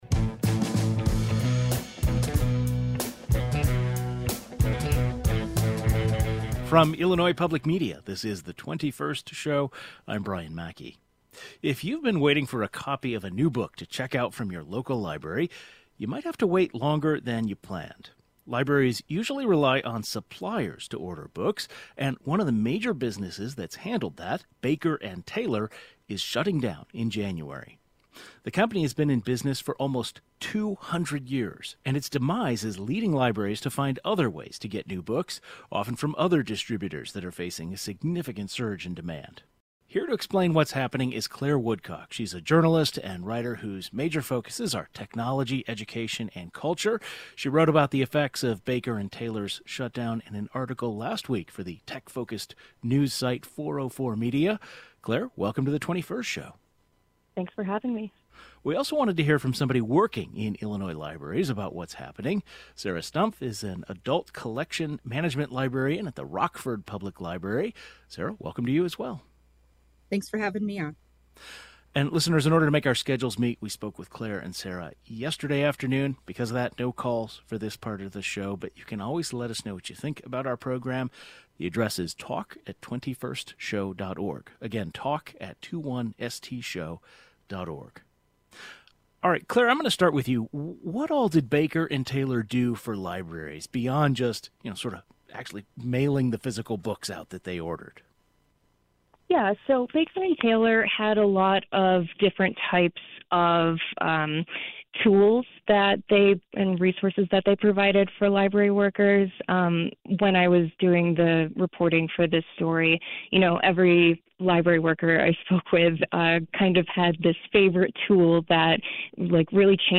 How is this affecting the work of Illinois libraries? A journalist who wrote about the shutdown as well as an Illinois-based librarian give their take.